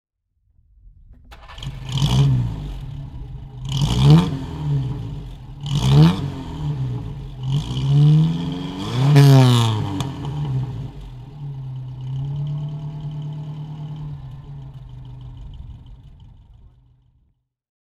Simca Aronde Plein Ciel (1960) - Starten des Motors
Simca_Aronde_Plein_Ciel_1960_-_Starten_des_Motors.mp3